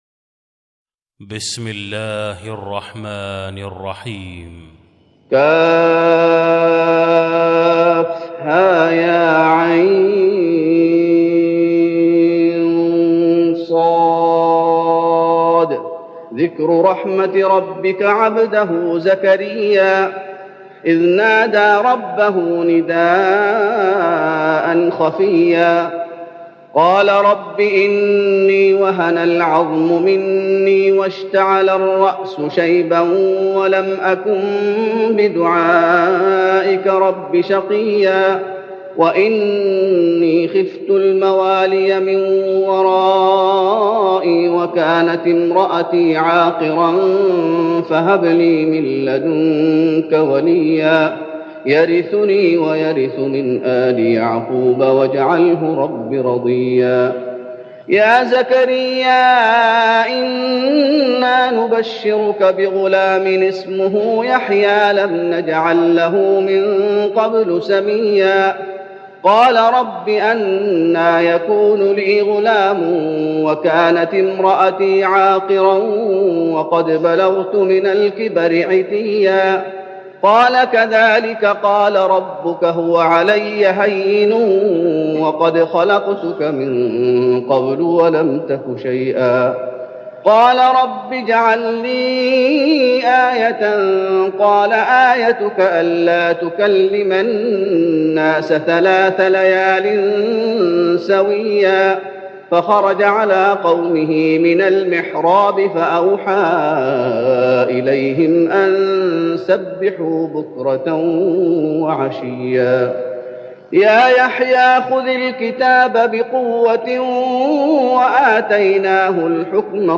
تهجد رمضان 1413هـ من سورة مريم (1-62) Tahajjud Ramadan 1413H from Surah Maryam > تراويح الشيخ محمد أيوب بالنبوي 1413 🕌 > التراويح - تلاوات الحرمين